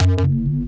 Garg Bass.wav